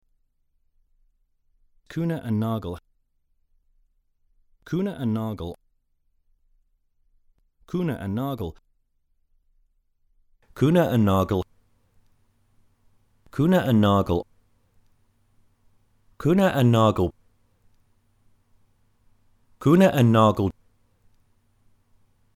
How do we pronounce Kuehne+Nagel?